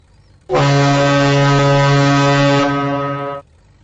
Cruise Ship Blaring